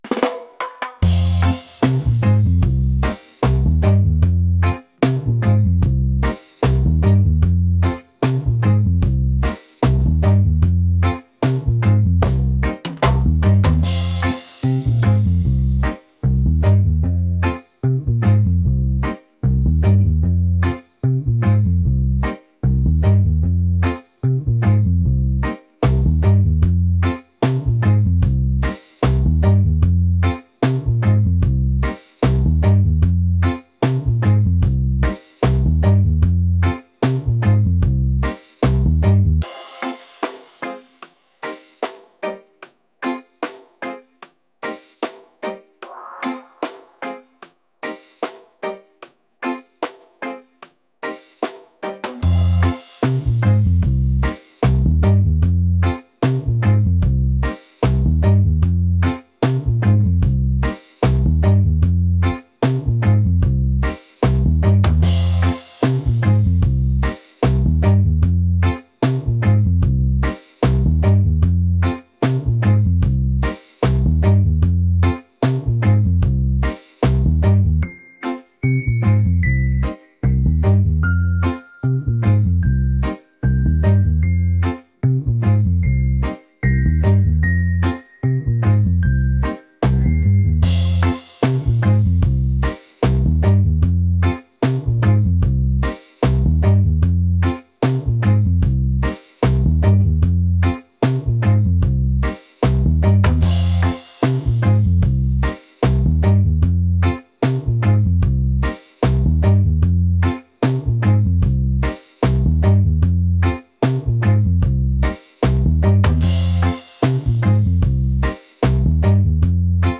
groovy | reggae | laid-back